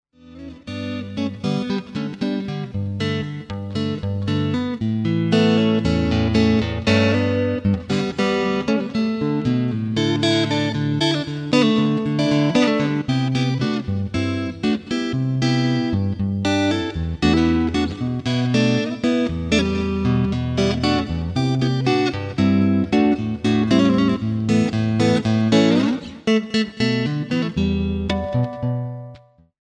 performed on solo guitar